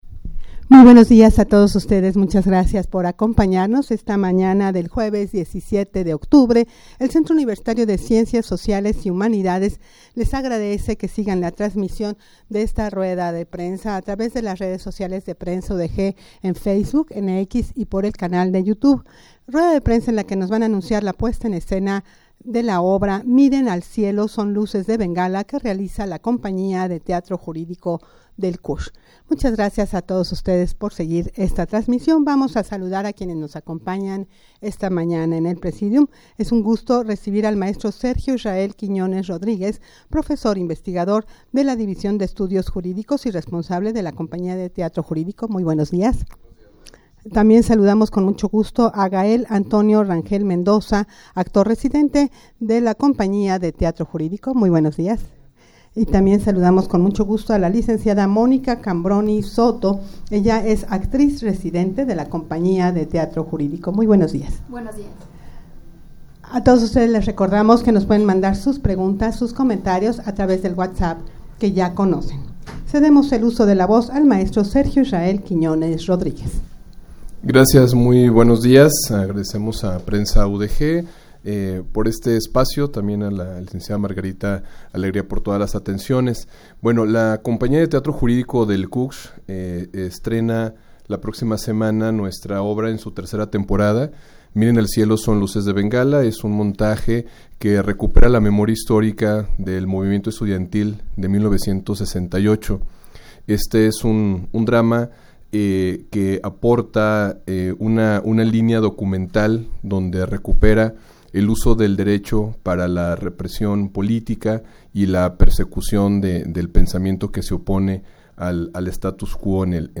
Audio de la Rueda de Prensa
rueda-de-prensa-para-anunciar-la-puesta-en-escena-miren-al-cielo.mp3